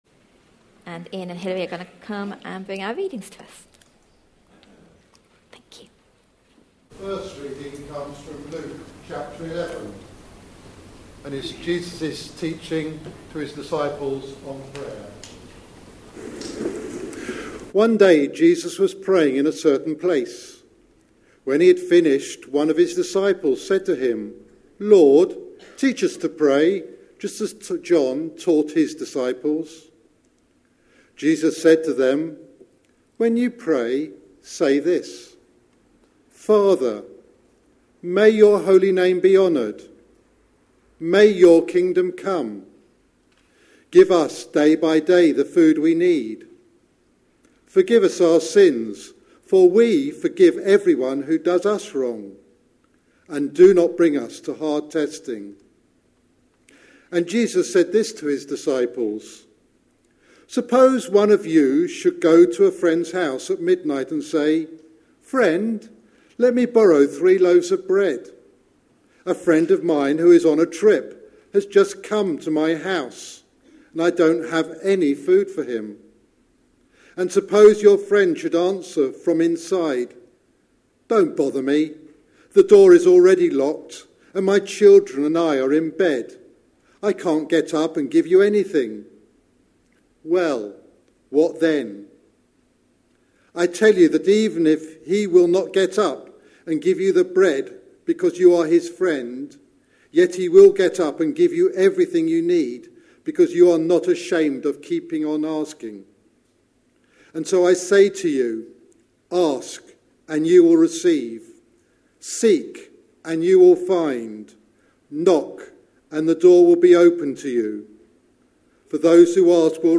A sermon preached on 3rd September, 2017, as part of our Radishes & Royalty: Growing as a Child of the Heavenly King. series.
Luke 11:1-13 Listen online Details Readings are Luke 11:1-13 and John 15:12-16, with a reference to the following week of prayer at CBC. This sermon was part of a service for Vision Sunday (with the same sermon in the evening).